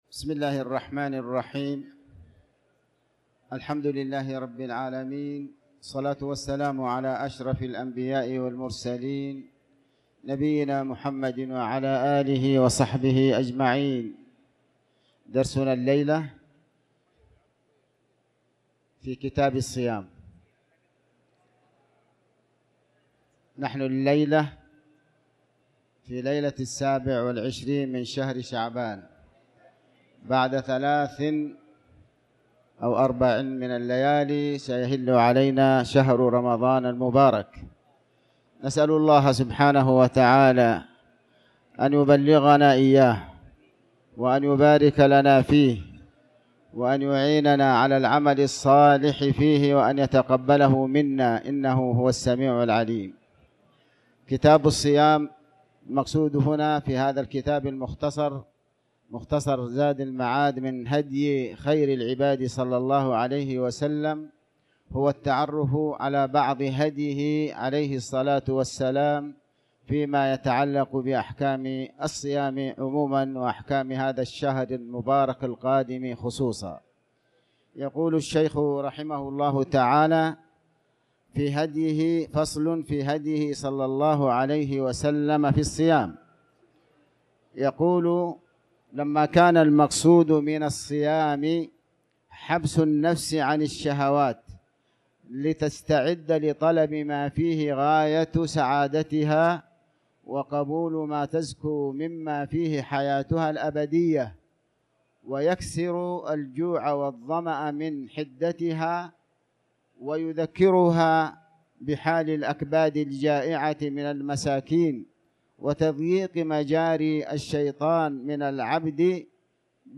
تاريخ النشر ٢٦ شعبان ١٤٤٠ هـ المكان: المسجد الحرام الشيخ: علي بن عباس الحكمي علي بن عباس الحكمي كتاب الصيام